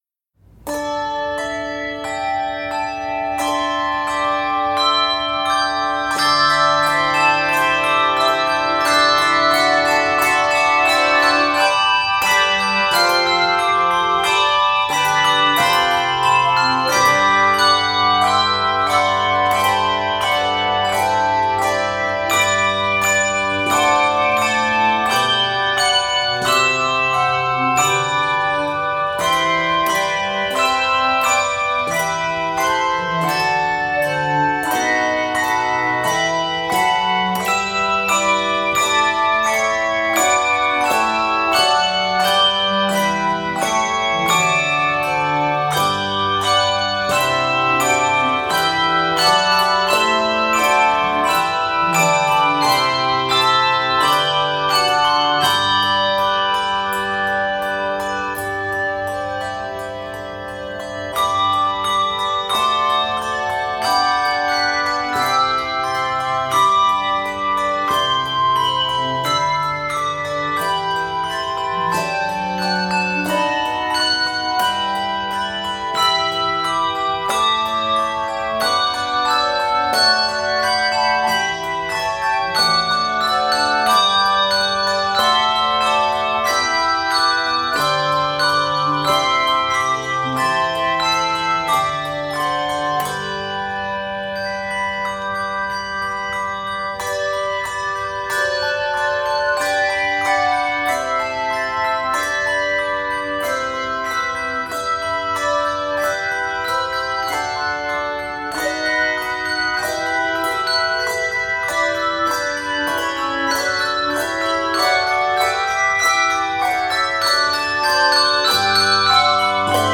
2-3 or 3-6 octave majestic arrangement